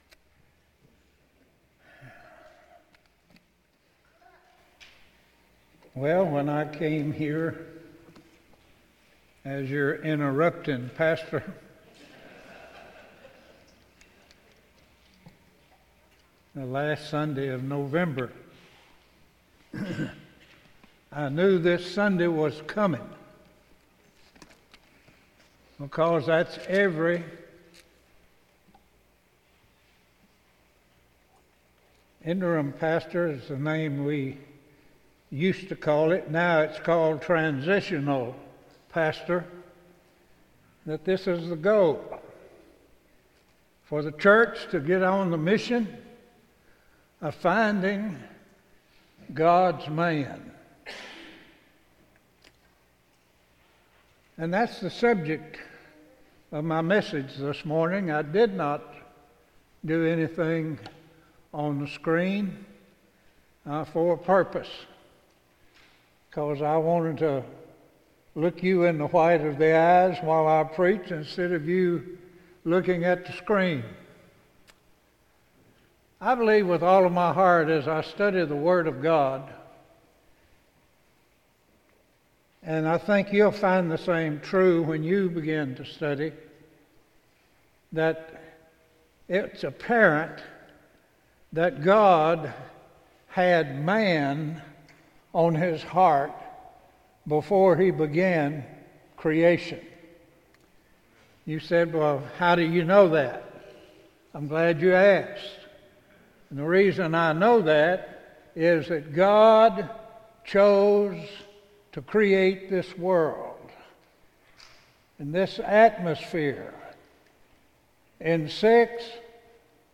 Morning Worship - Central Baptist Church